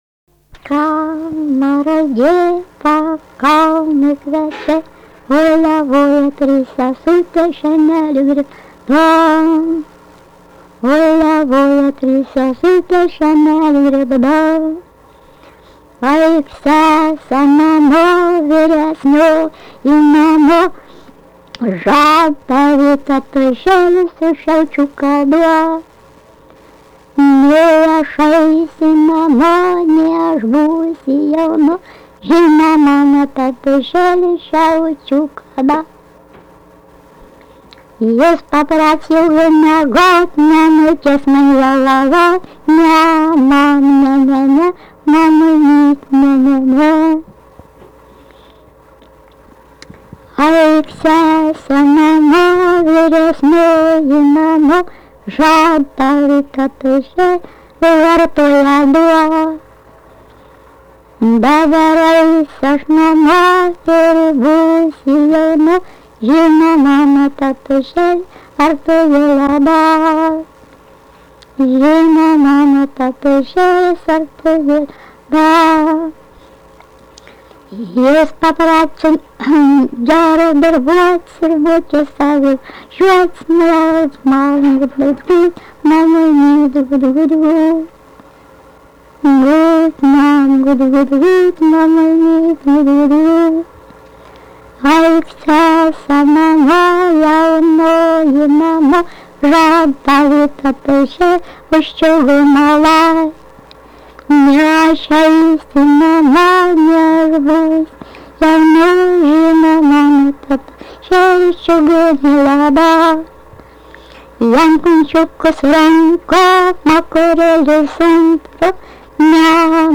Dalykas, tema daina
Erdvinė aprėptis Ryžiškė Vilnius
Atlikimo pubūdis vokalinis